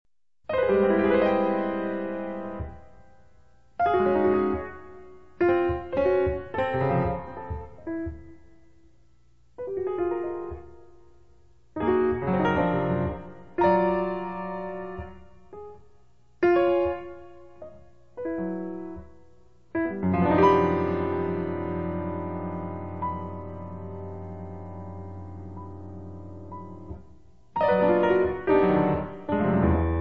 bass
drums
piano
• jazz